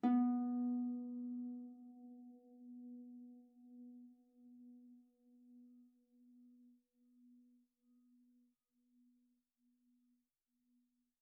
KSHarp_B3_mf.wav